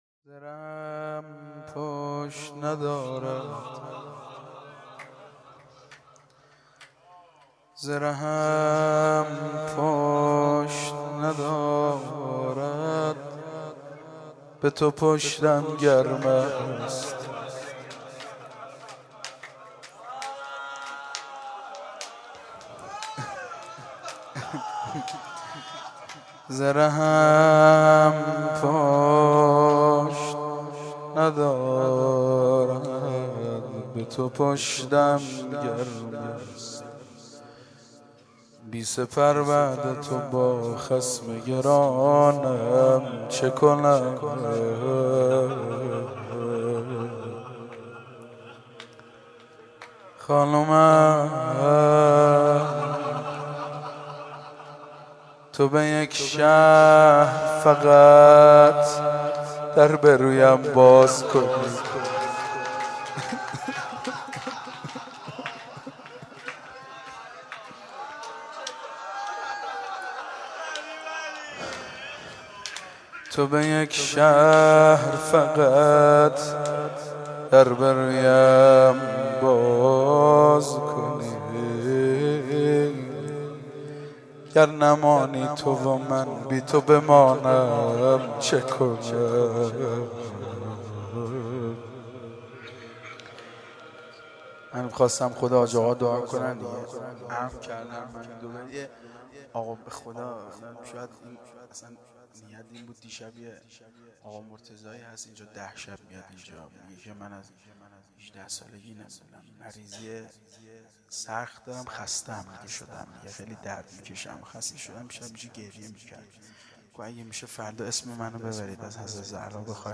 10-Rozeh.mp3